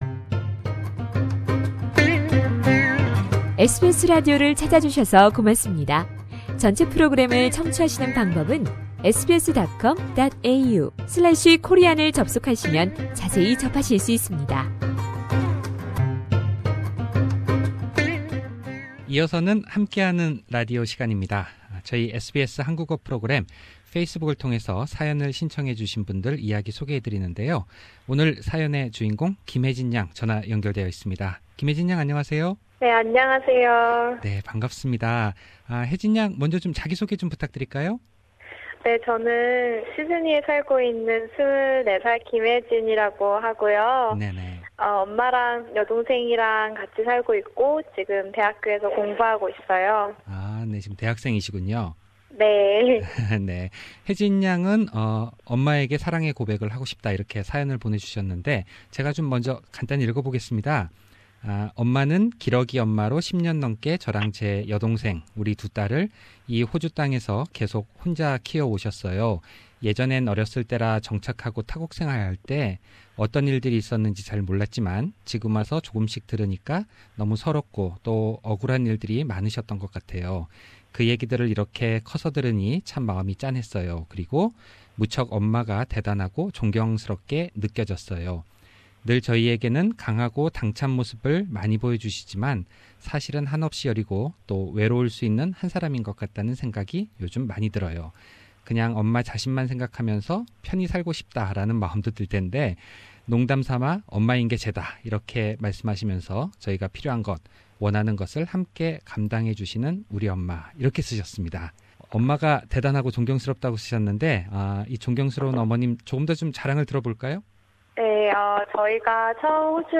Facebook friends of SBS Korean program participated in the radio program by sending their love letters to parents in Korea, Mom and Husband in Australia.